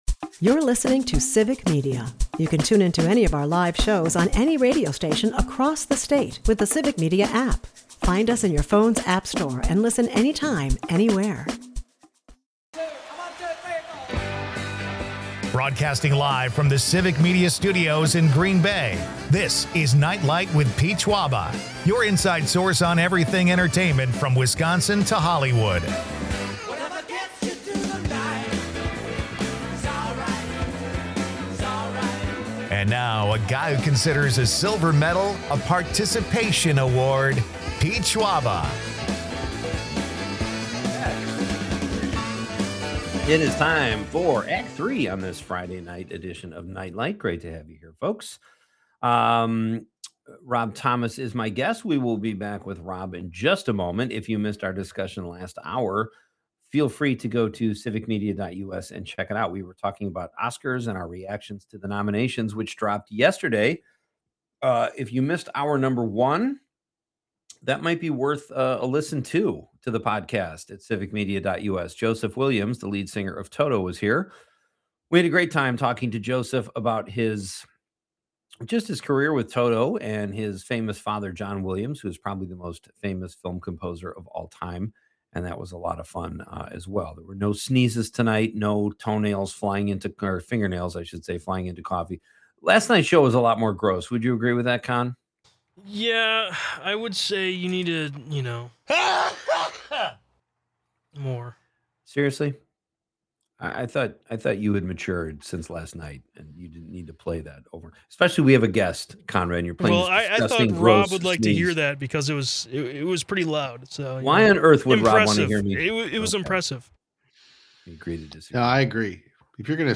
The show also covers Marinette's history and quirky stories, like a man preserving a 50-year-old snowball. The night wraps up with a spirited quiz on Marinette and past show guests, sparking laughs and nostalgia.